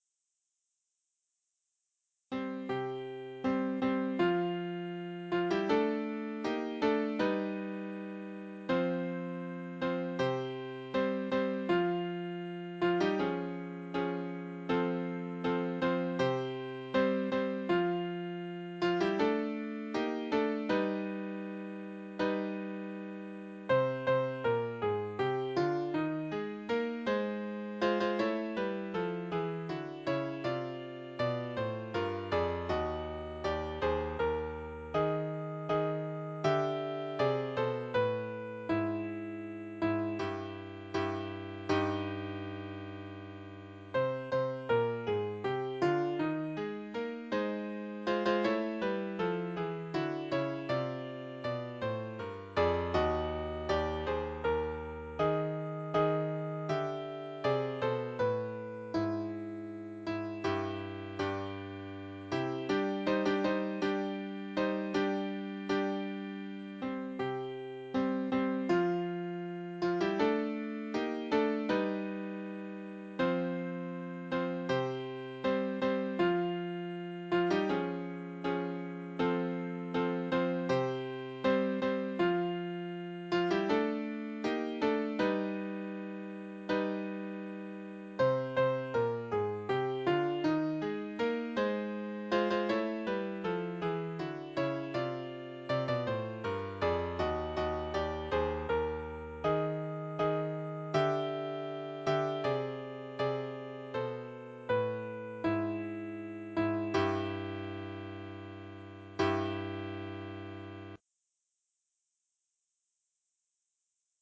Michaeli, Bibelsprüche für dreistimmigen Chor